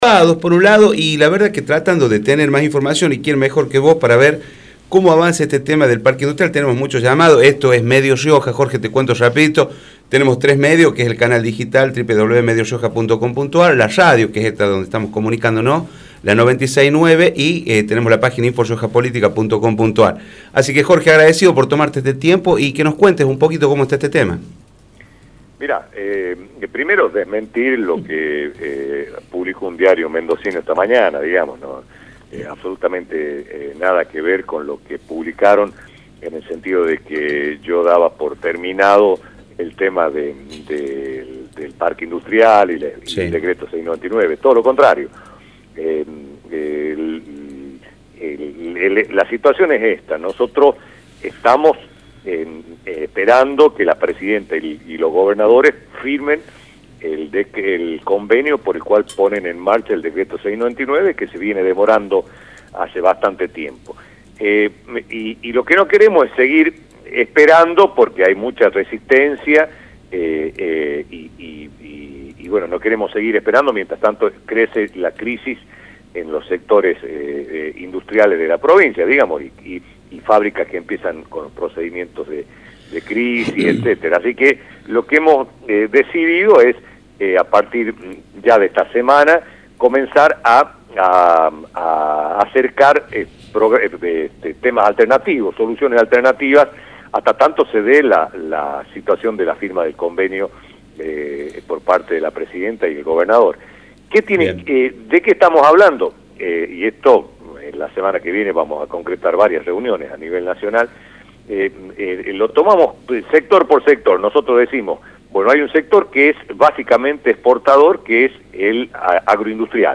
Nancy Nardillo, titular de Empleo municipal, por Radio Rioja
jorge-yoma-diputado-nacional-por-radio-rioja1.mp3